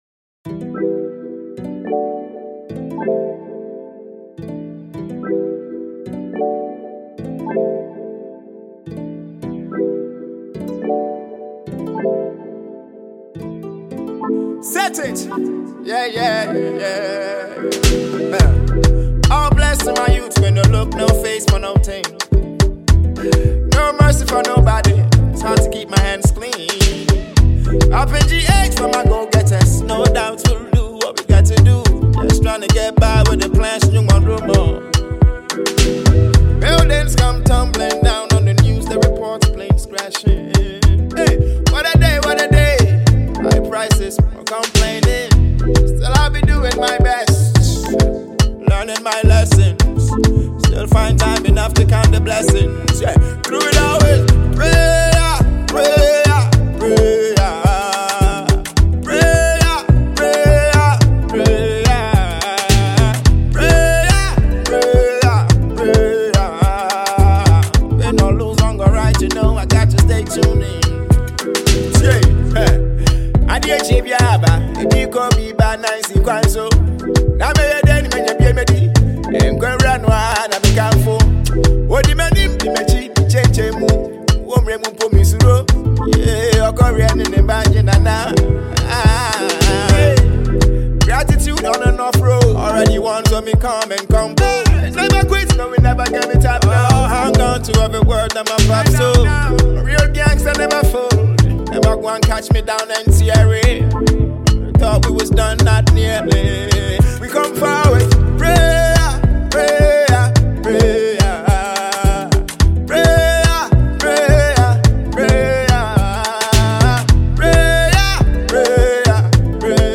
an inspiring tune